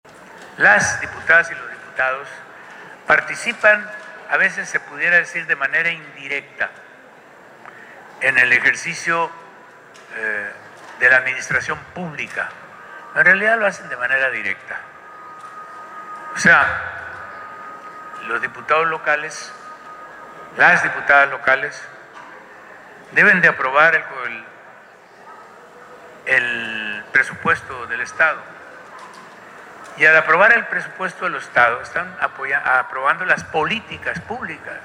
Guasave, Sinaloa 18 de octubre de 2025.- Atendiendo la invitación de los representantes del Partido Verde Ecologista de México, el gobernador Rubén Rocha Moya, acudió, al primer informe de labores de la y el diputado del grupo parlamentario, por los distritos 07 y 08, quienes, en un acto de rendición de cuentas ante la sociedad compartieron los resultados de su trabajo del último año en el Congreso del Estado.